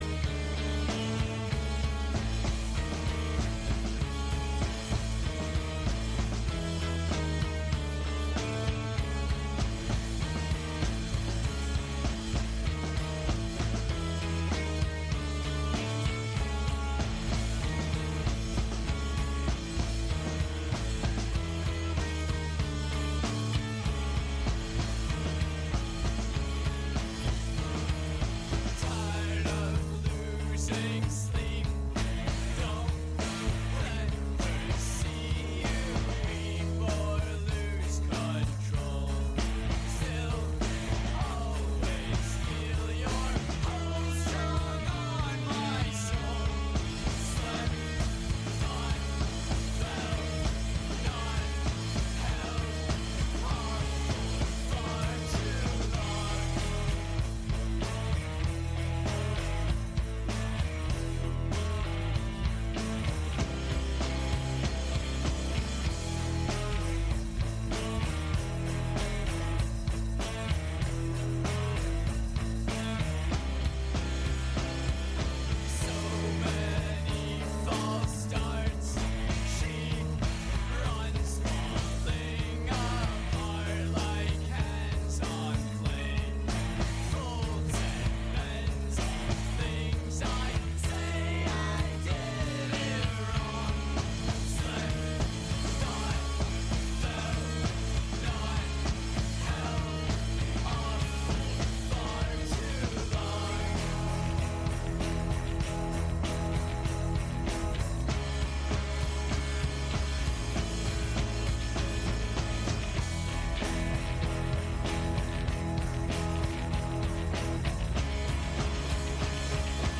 an ep full of sick ass tunes between poignant observations & appeals